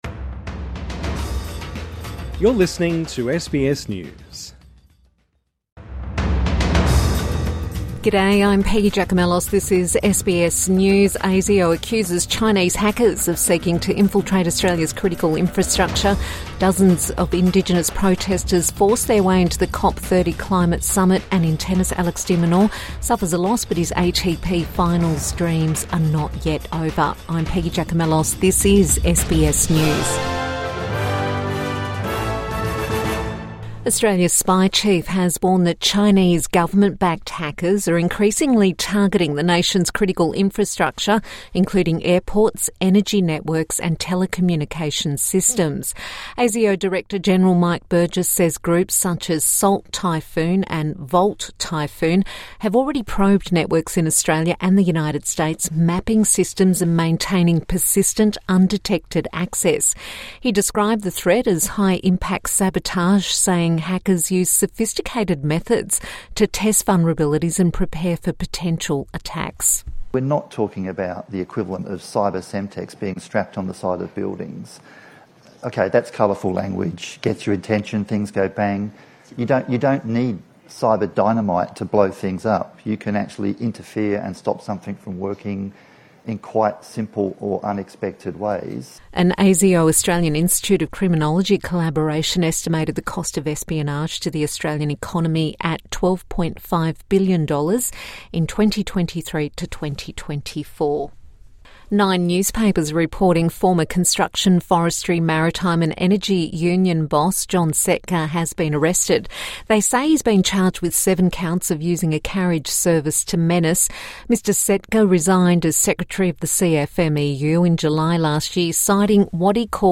ASIO issues a warning about Chinese hackers |Midday News Bulletin 12 November 2025